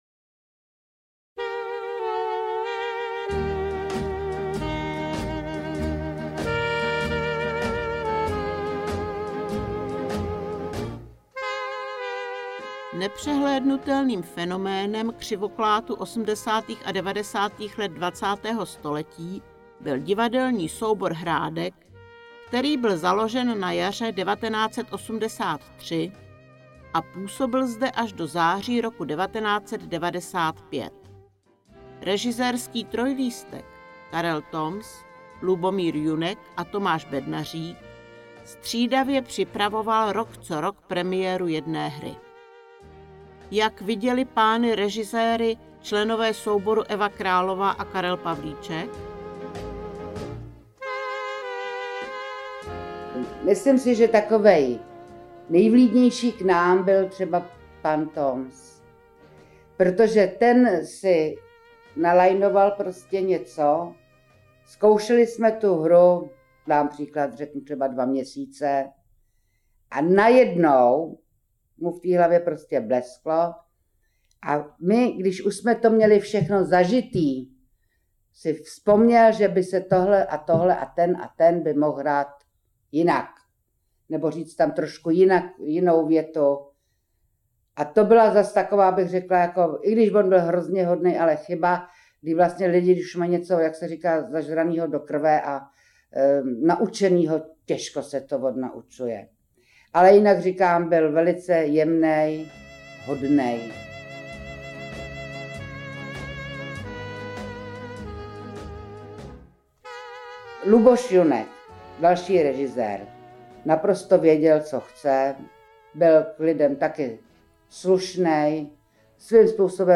Komentované listinné a obrazové dokumenty, vzpomínky křivoklátských ochotníků.